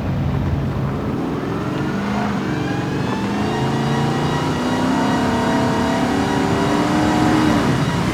Index of /server/sound/vehicles/lwcars/eldorado
rev.wav